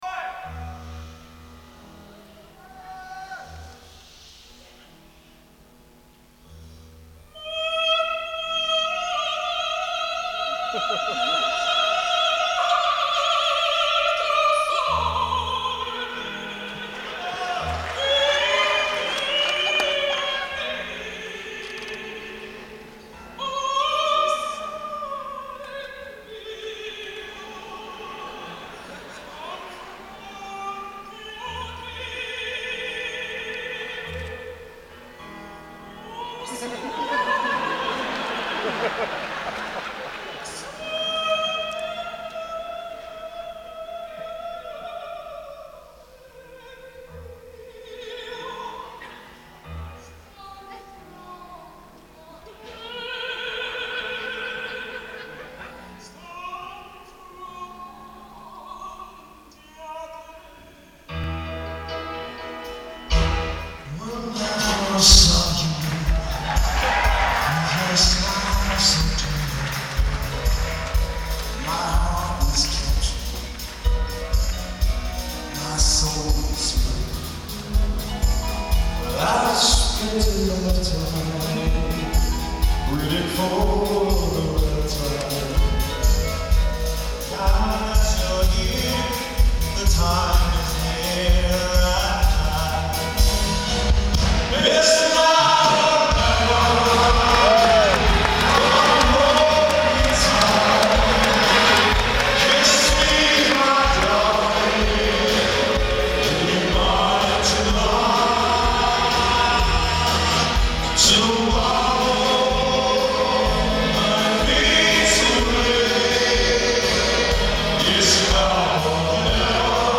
First performed: April 12, 1995 (New York City, NY)